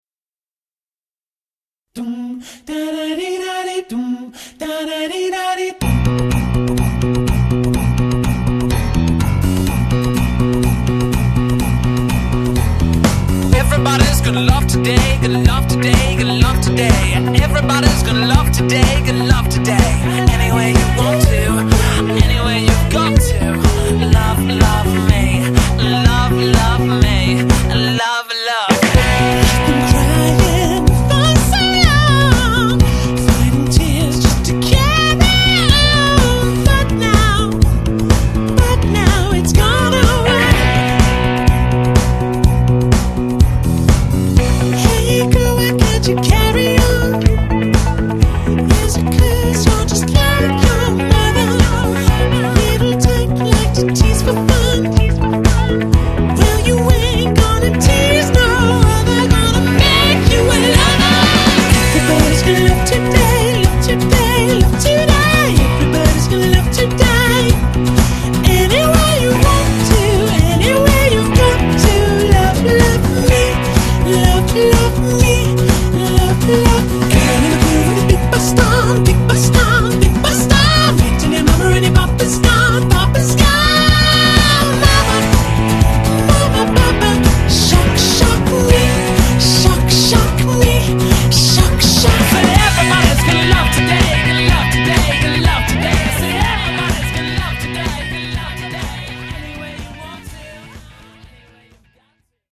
Audio QualityPerfect (High Quality)
Enjoy the high-pitched fun sound